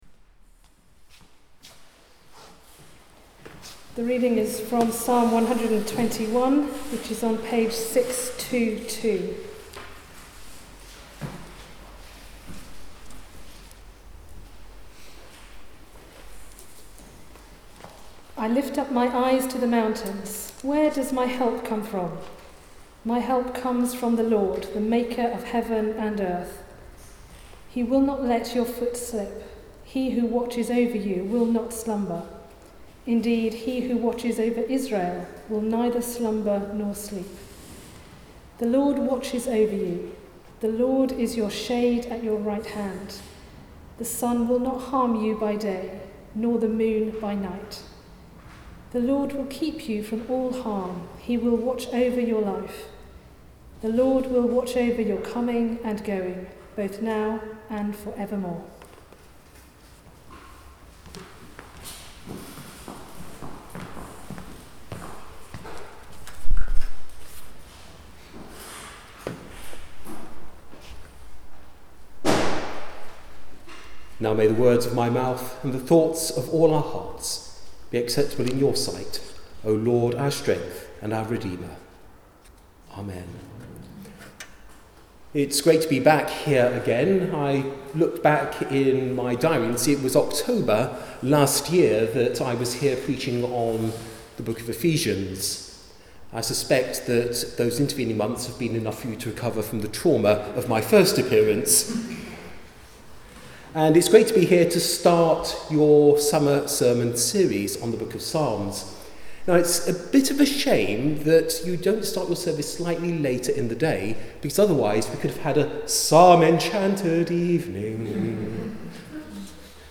Passage: Psalm 121 Service Type: Weekly Service at 4pm Bible Text